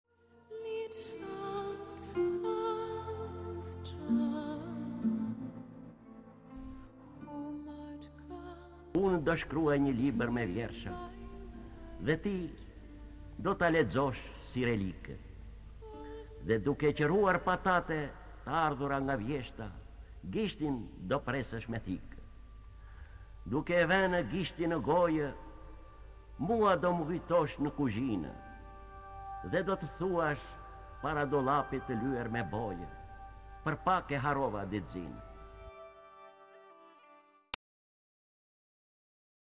D. AGOLLI - DO MË KUJTOSH Lexuar nga D. Agolli KTHEHU...